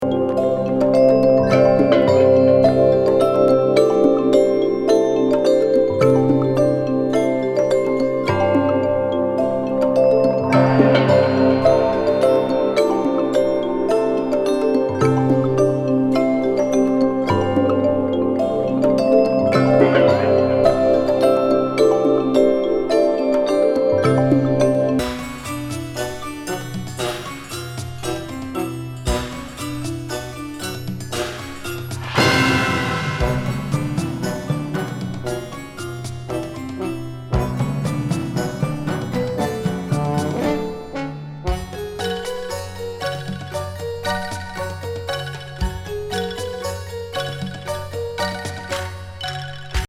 マリンバ、ヴィブラフォン、ダルシマー、
ベル、シンバルにチャイムの一人アンサンブルにシンセが乗る極上